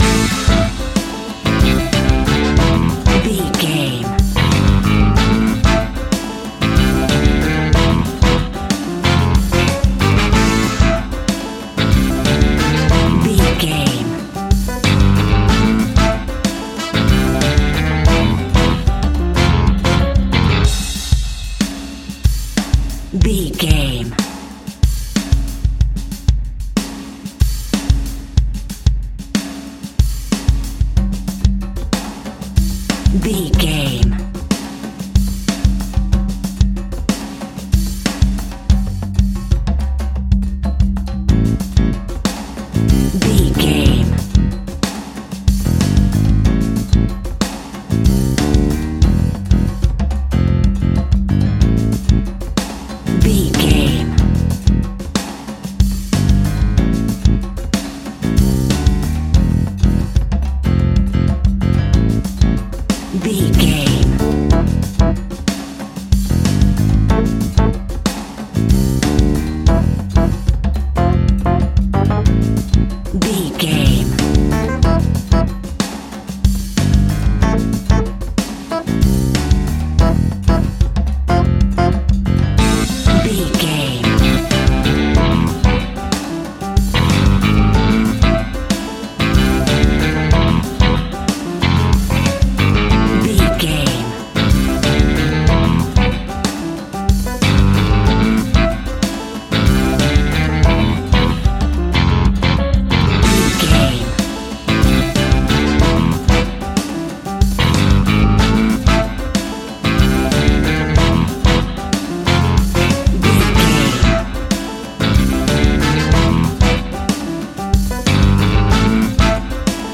Aeolian/Minor
flamenco
latin
uptempo
drums
bass guitar
percussion
saxophone
trumpet
fender rhodes
clavinet